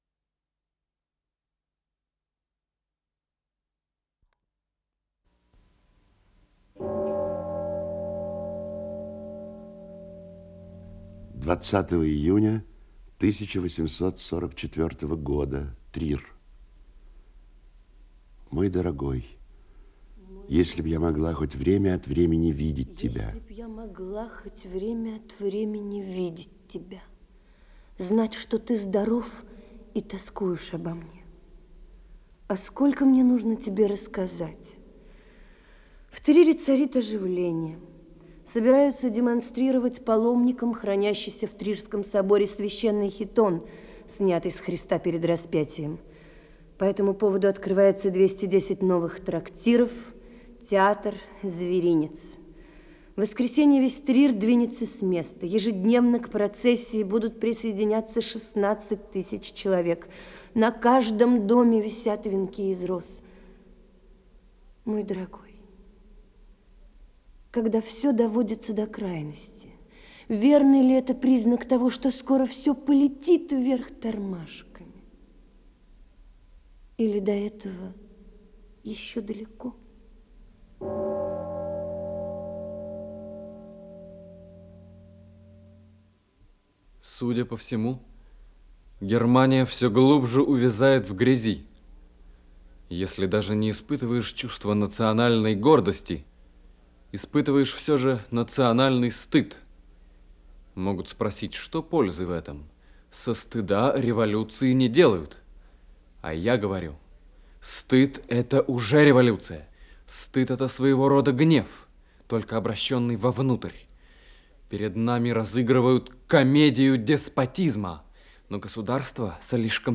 Исполнитель: Артисты московского театра им. М. Н. Ермоловой
Радиокомпозиция спектакля Московского театра им. М. Н. Ермоловой